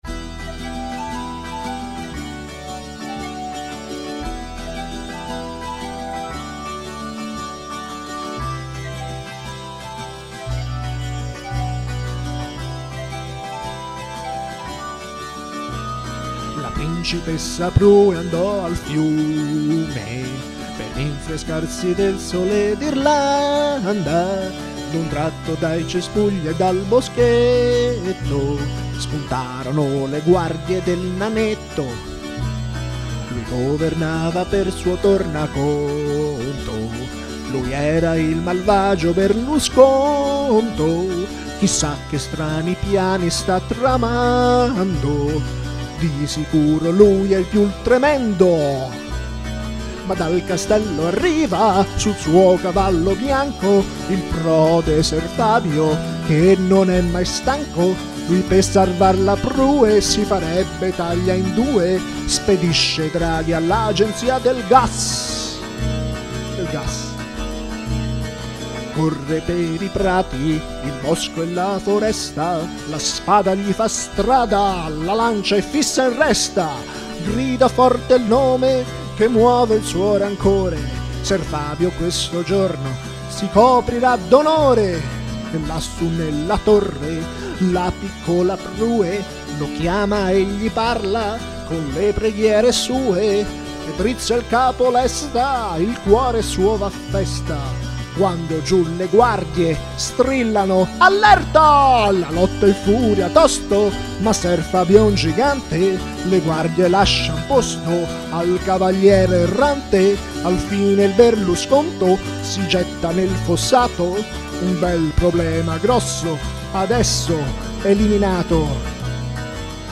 canzone celtica
mi ha ispirato questa ballata altamente epica